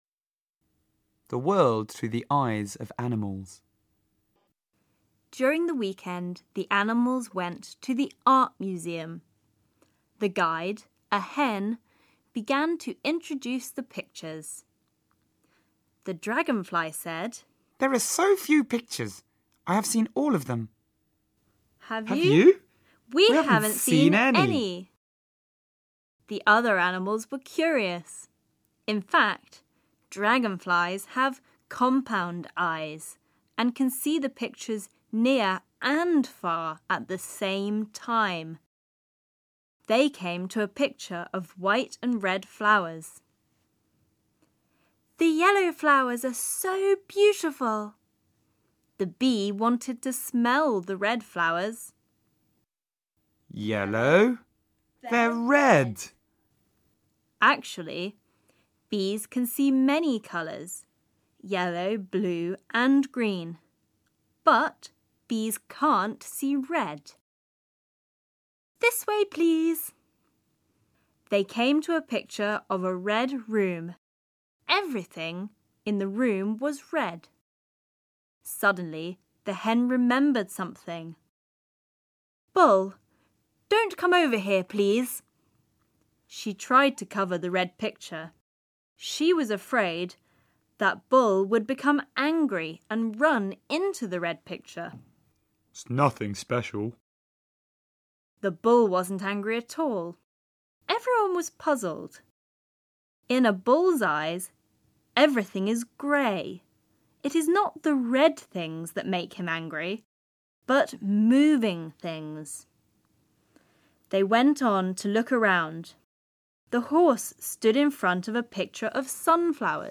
Chỉ cần có một thiết bị điện tử kết nối mạng, trẻ sẽ ngay lập tức được nghe câu chuyện qua giọng kể của người bản ngữ.